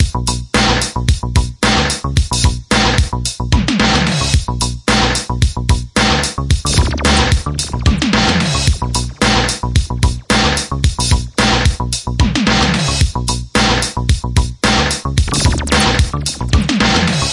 描述：以复古，犯罪动作电影的声音为灵感。